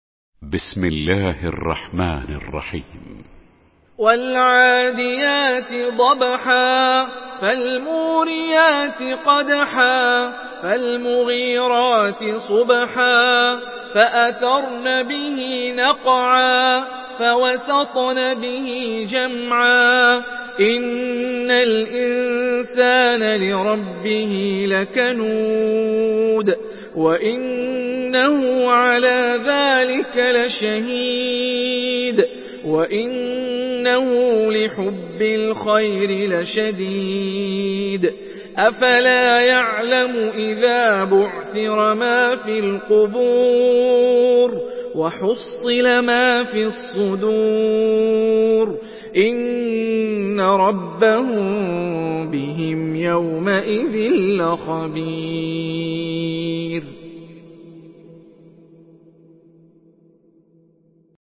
تحميل سورة العاديات بصوت هاني الرفاعي
مرتل